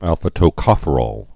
(ălfə-tō-kŏfə-rôl, -rōl)